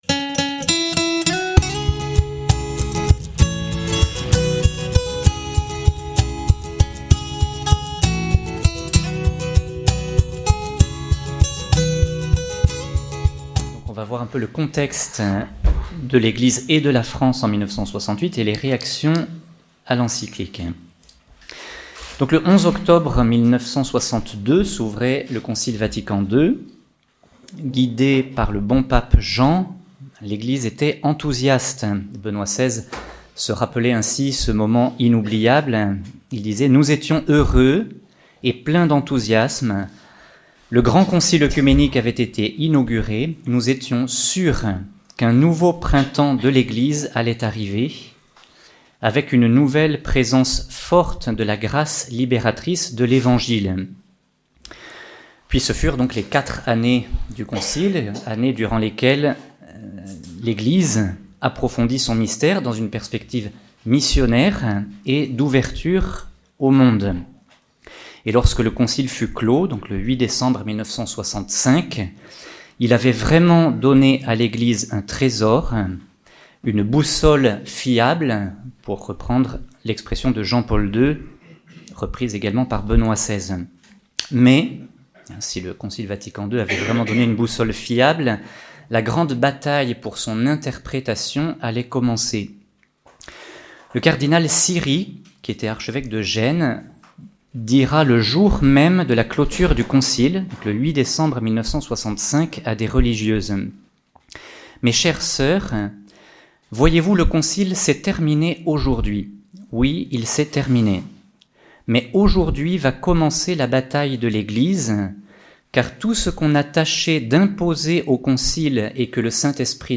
Conférence de la semaine